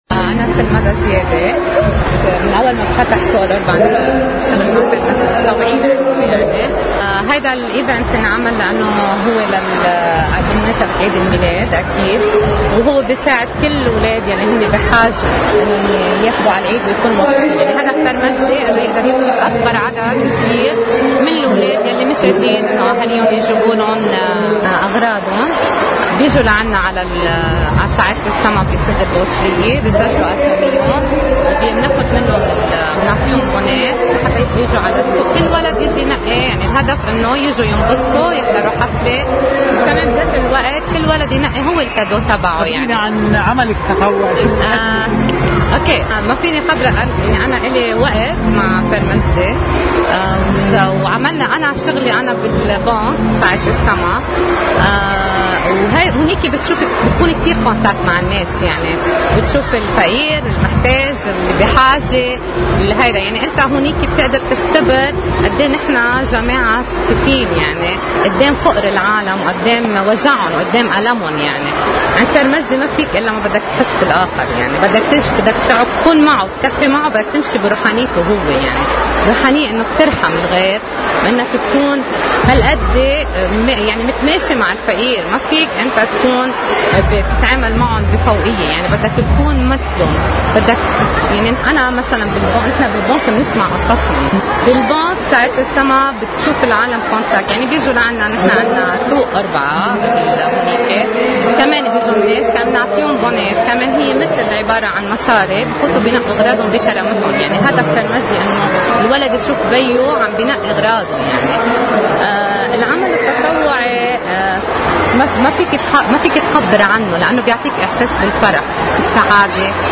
خاص توزيع هدايا عيد الميلاد من “سعادة السما” بالـ”فوريم دي بيروت” (Video+Audio+Pictures) – Agoraleaks